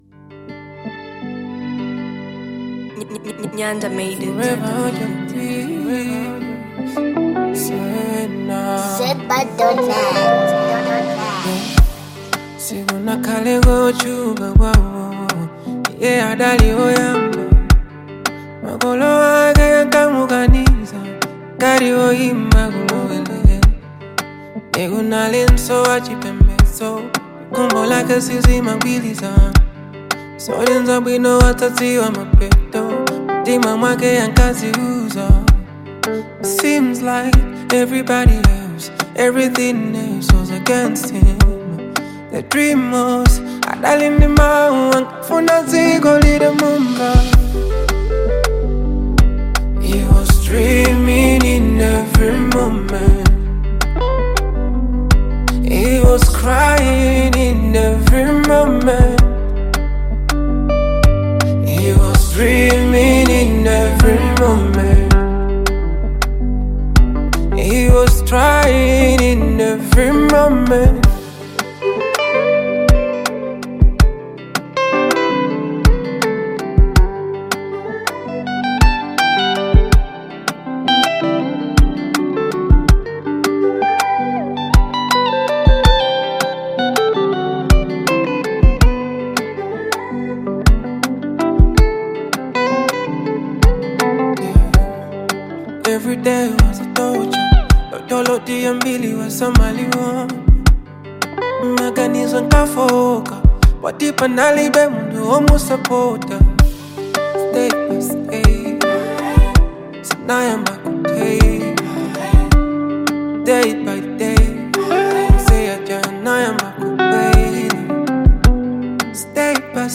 With its soft beats and soothing tones